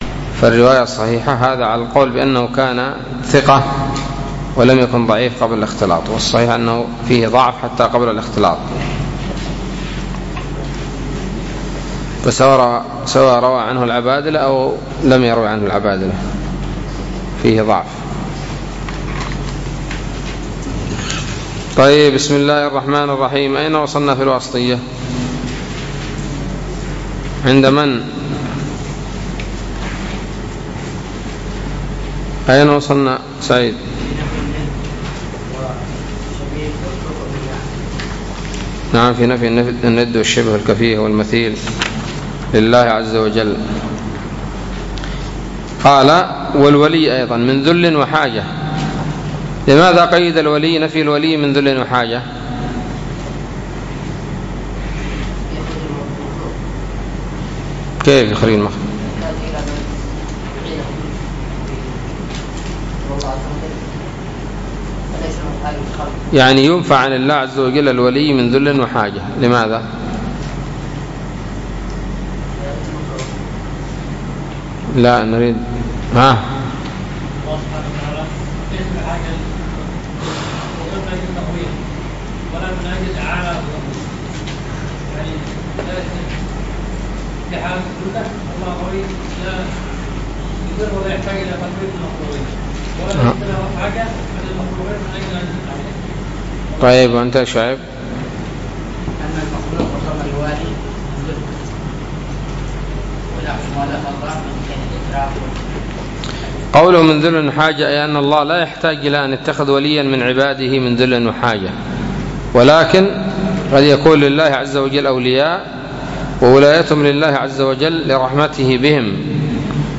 الدرس التاسع والستون من شرح العقيدة الواسطية